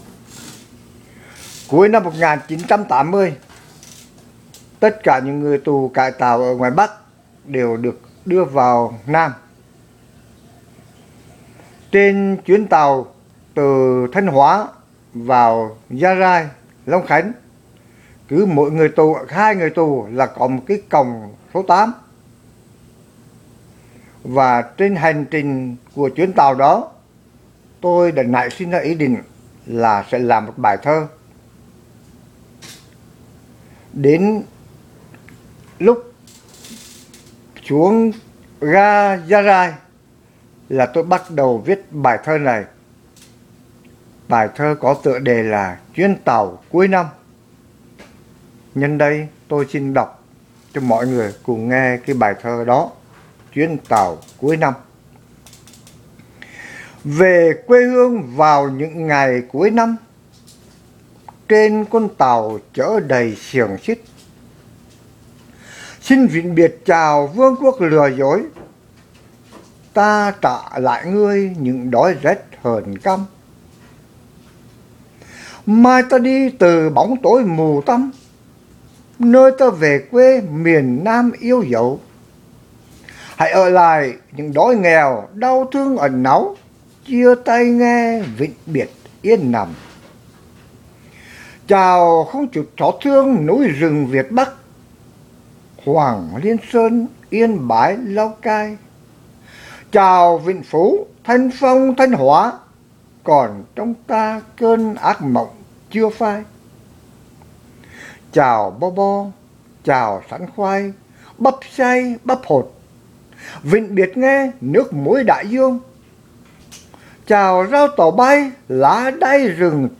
Xin bấm vào đây để nghe phần diễn đọc bài thơ của chính tác giả: